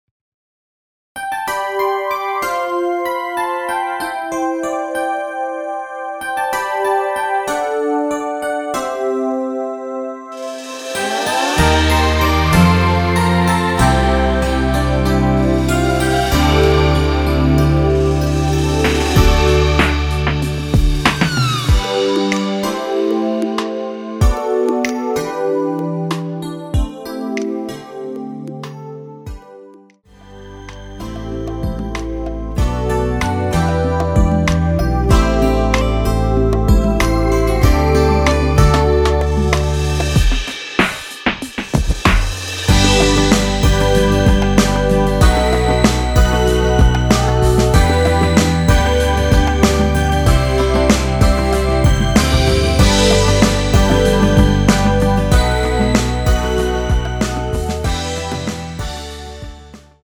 여성분이 부르실수 있는키의 MR입니다.(미리듣기 확인)
원키에서(+3)올린 MR입니다.
앞부분30초, 뒷부분30초씩 편집해서 올려 드리고 있습니다.
중간에 음이 끈어지고 다시 나오는 이유는